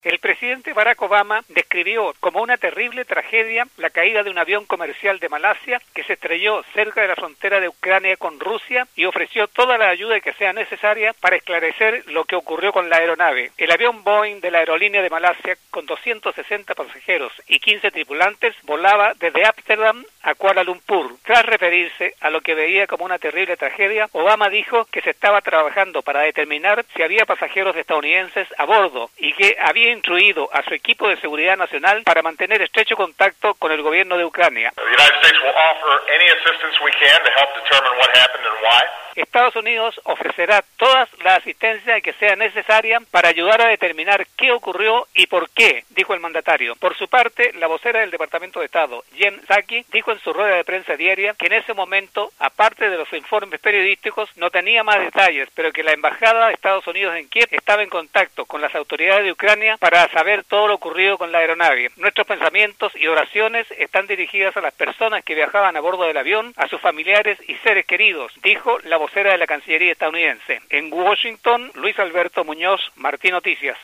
desde Washington